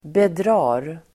Uttal: [bedr'a:r]